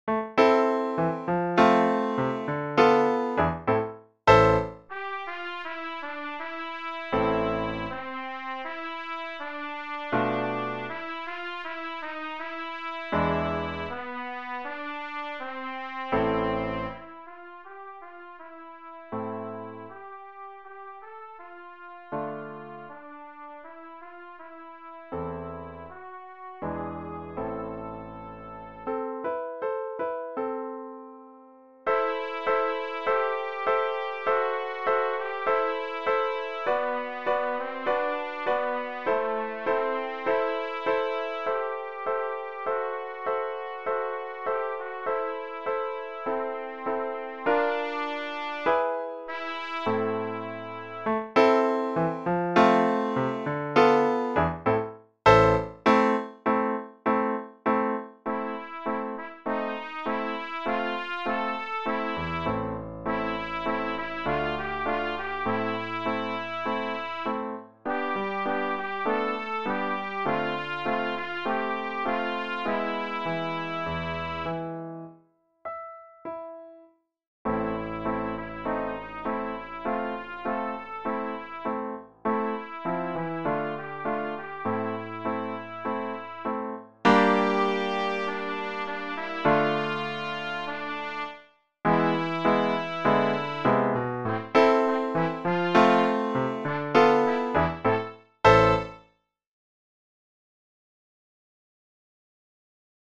Pour trompette (ou cornet) et piano DEGRE CYCLE 1